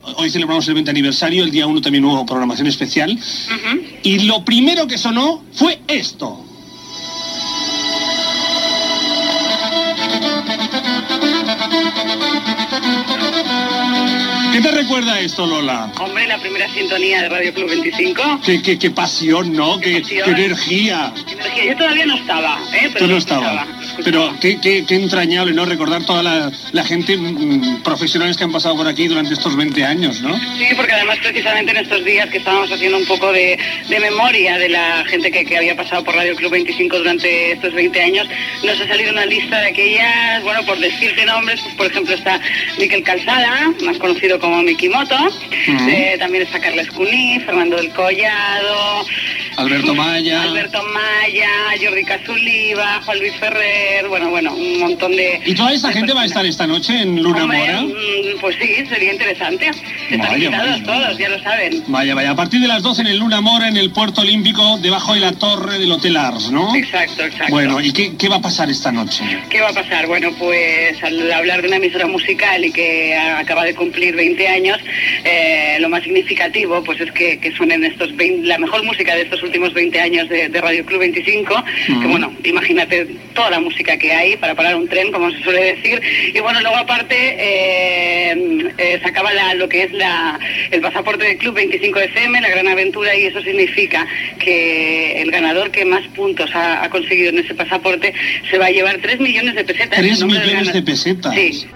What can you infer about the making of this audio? Fragment de l'especial 20è aniversari de l'emissora.